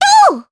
Luna-Vox_Attack3_jp.wav